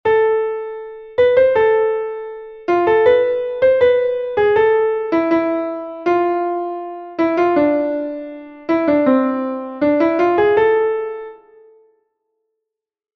Entoación a capella
Melodía 2/4 en La m